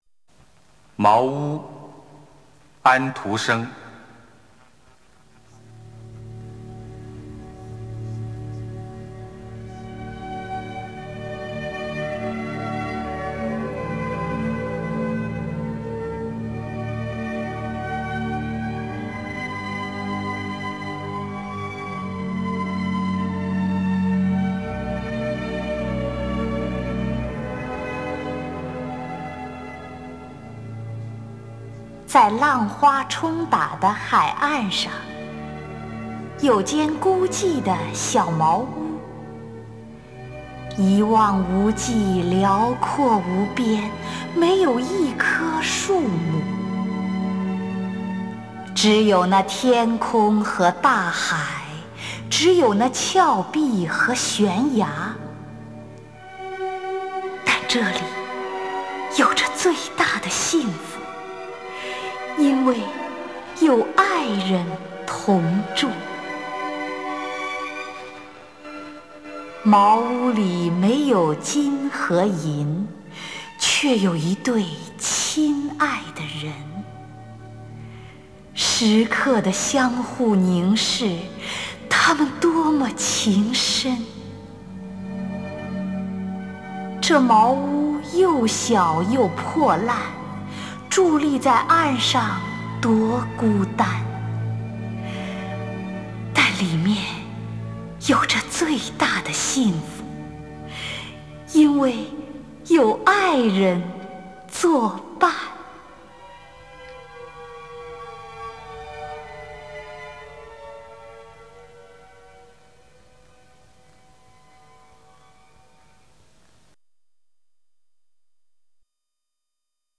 丁建华朗诵：《茅屋》(（丹麦）汉斯·克里斯蒂安·安徒生)